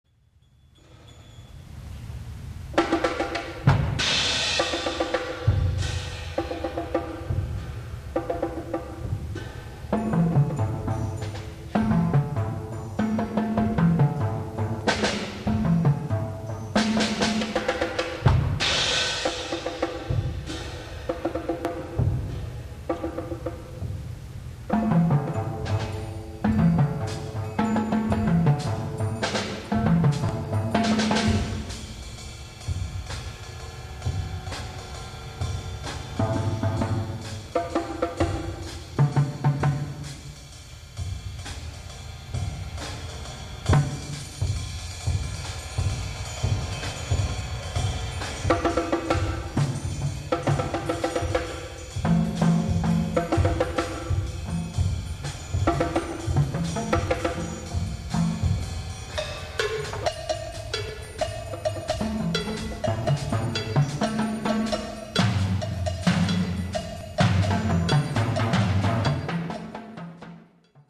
(or two cowbells).